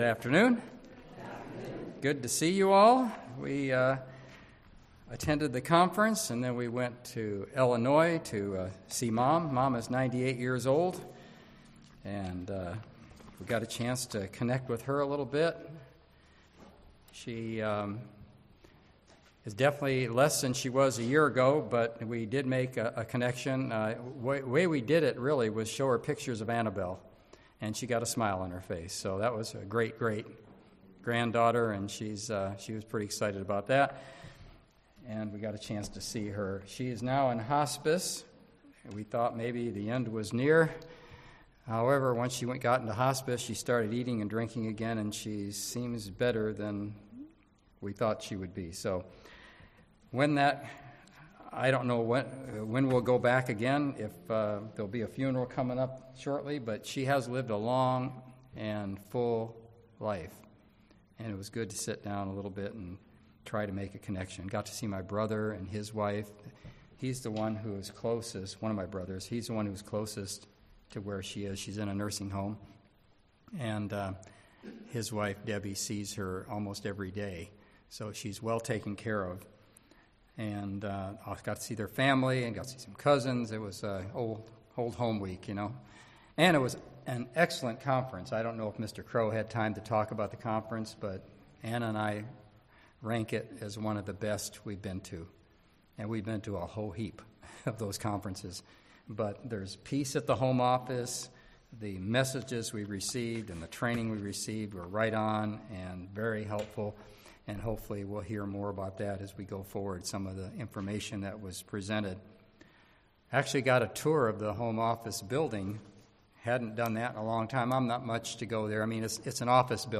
Listen to this sermon and find out what "The Path to Wisdom" is, and how to walk that path.